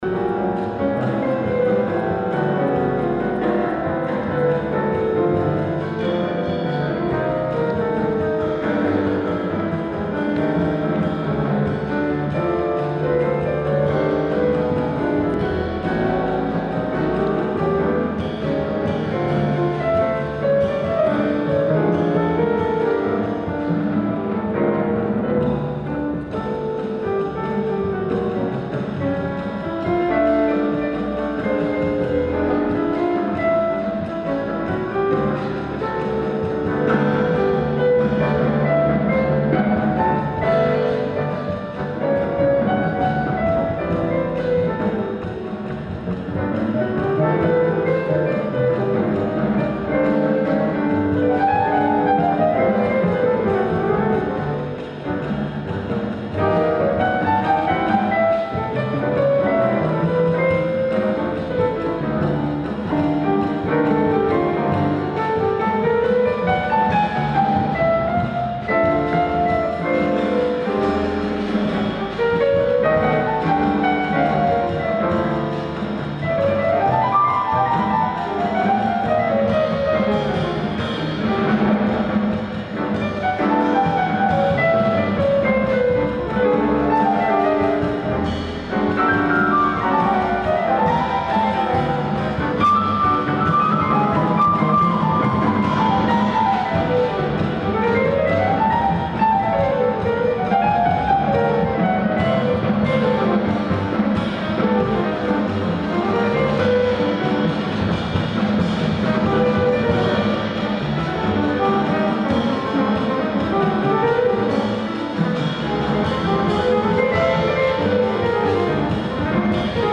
ライブ・アット・カーネギー・ホール、ニューヨーク 06/17/2010
※試聴用に実際より音質を落としています。